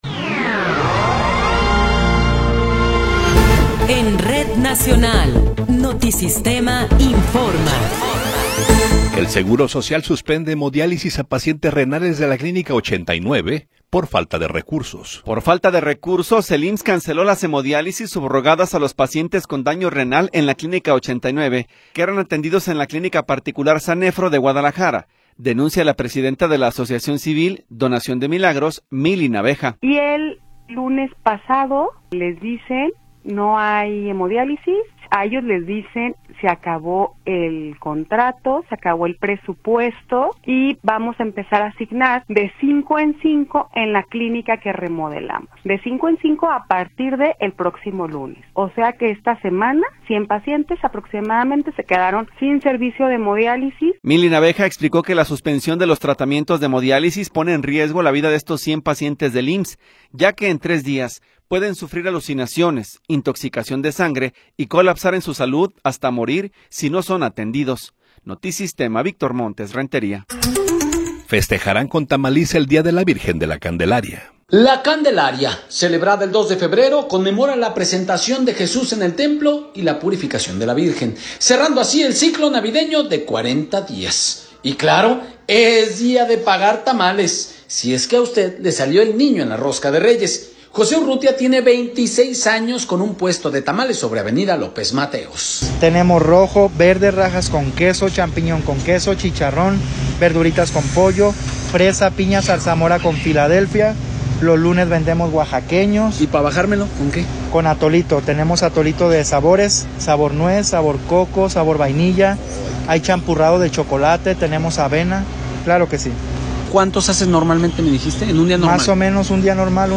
Noticiero 13 hrs. – 28 de Enero de 2026